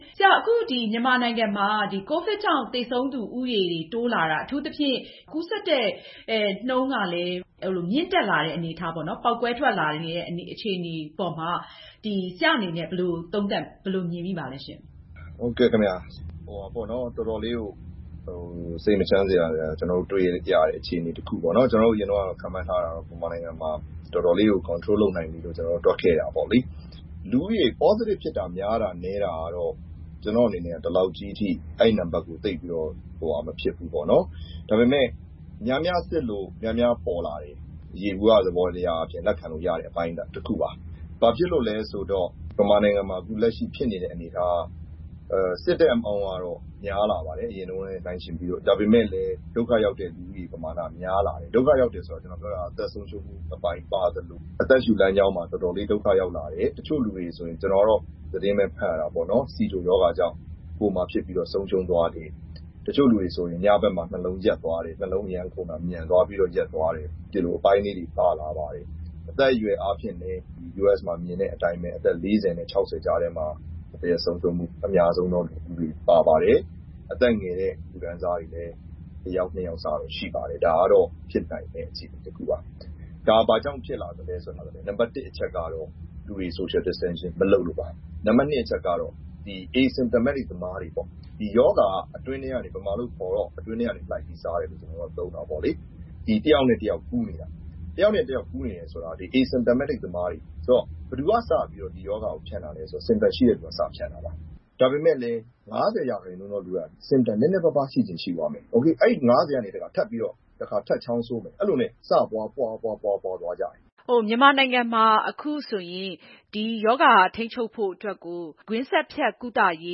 Skype ကနေ ဆက်သွယ်မေးမြန်းထားပါတယ်။